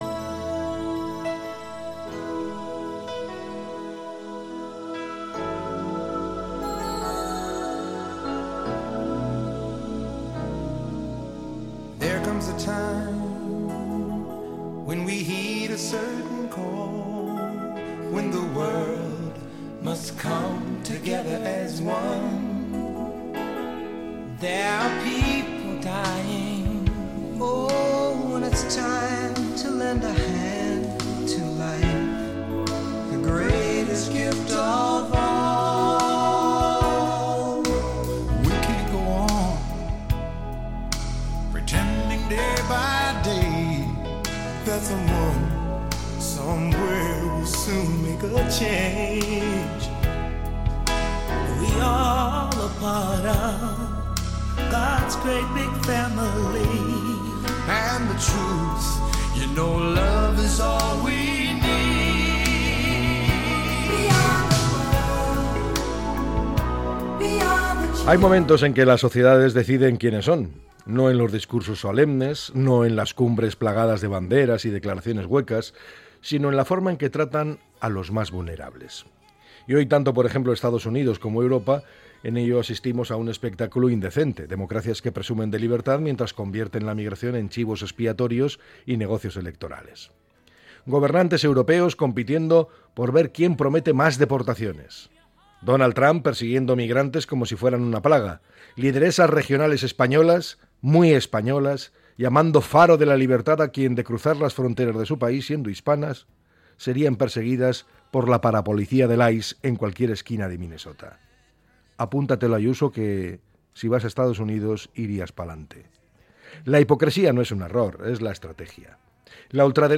Podcast Opinión